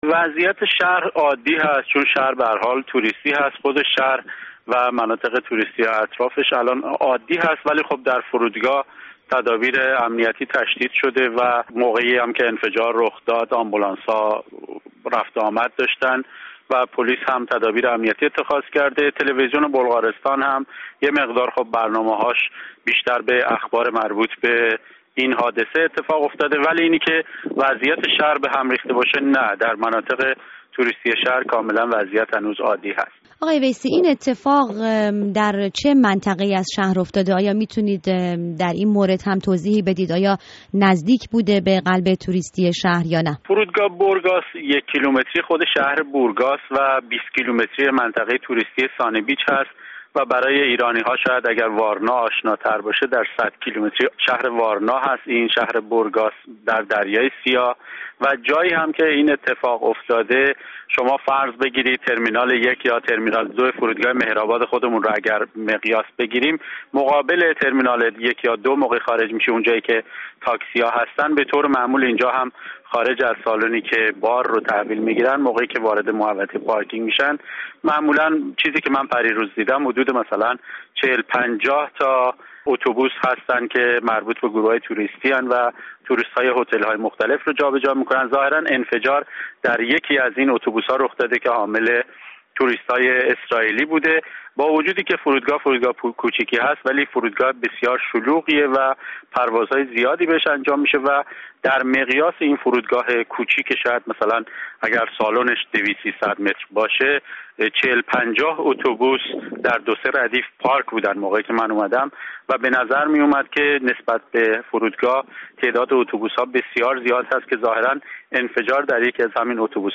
گزارش رادیو فردا از شهر بورگاس پس از انفجار در اتوبوس اسرائیلی‌ها
گفت‌وگو